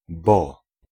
Ääntäminen
France (Paris): IPA: [kaʁ]